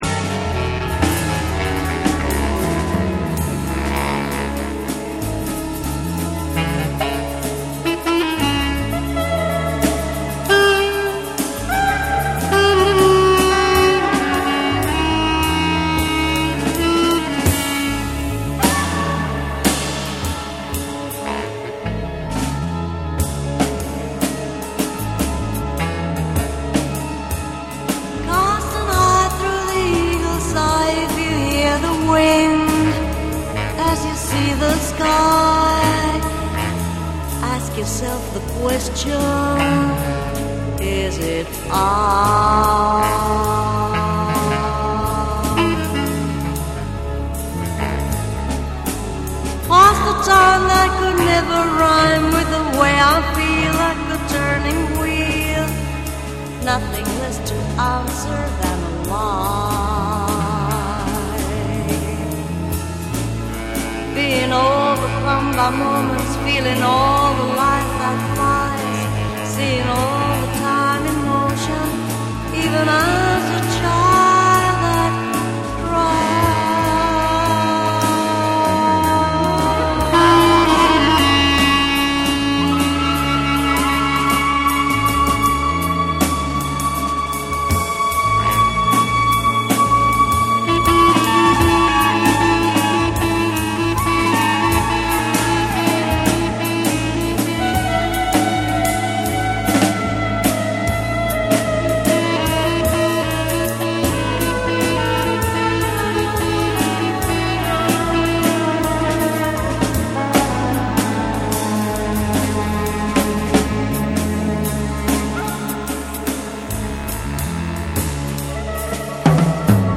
ジャズ、エレクトロニクス、詩的な語りが溶け合う、まさにECMならではの冬の記憶。